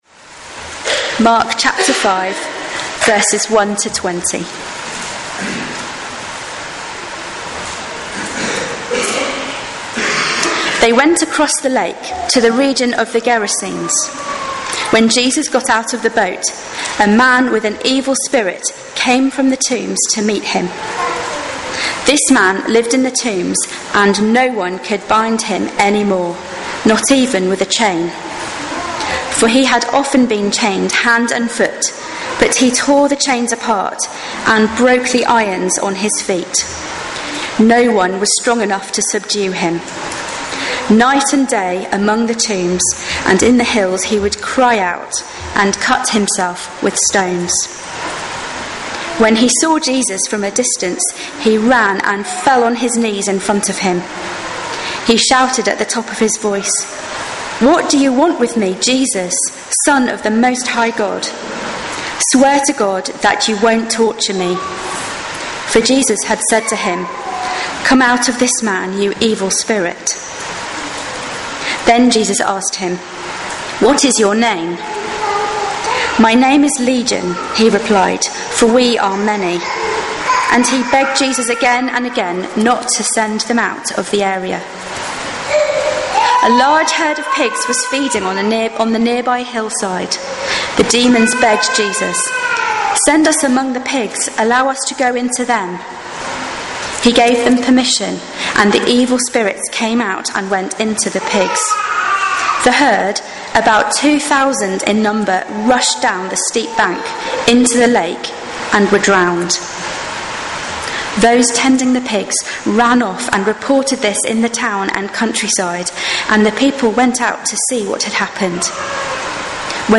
Sermons Archive - Page 93 of 188 - All Saints Preston